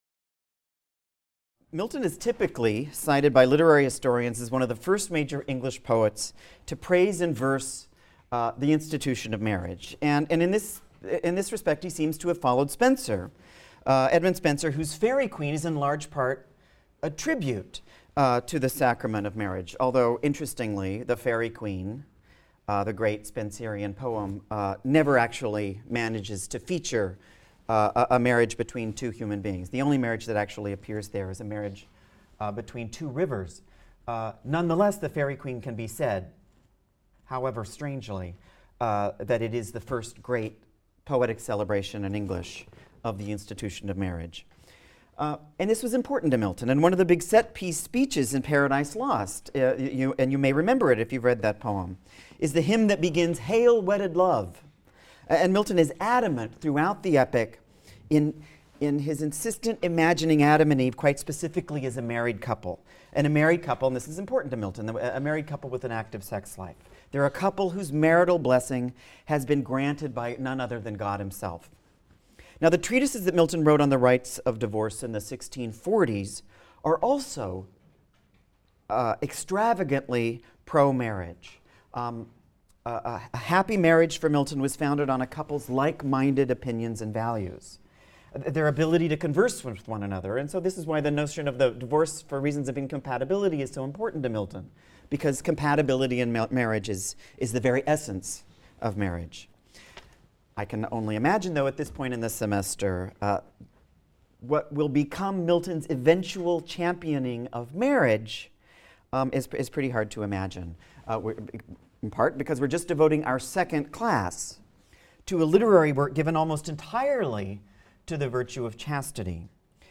ENGL 220 - Lecture 5 - Poetry and Marriage | Open Yale Courses